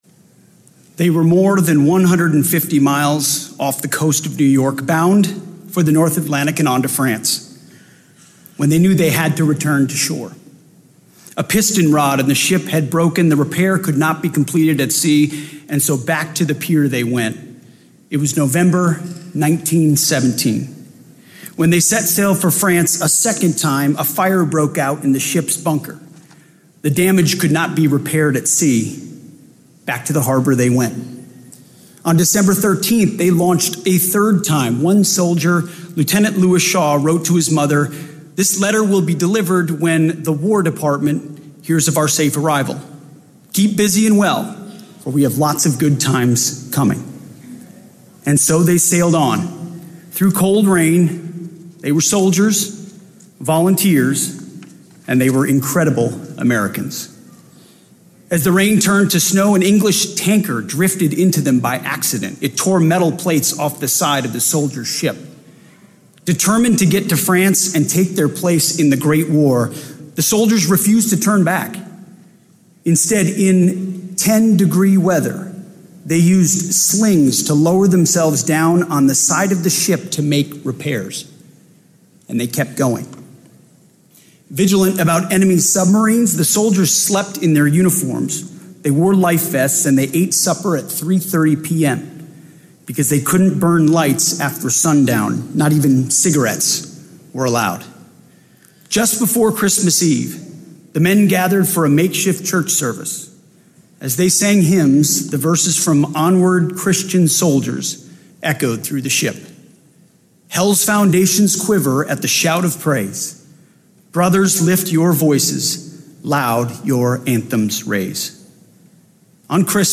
Remarks at the Congressional Gold Medal Ceremony for the Harlem Hellfighters
delivered 3 September 2025, Emancipation Hall, U.S. Capitol Visitor Center, Washington, D.C.
Audio Note: AR-XE = American Rhetoric Extreme Enhancement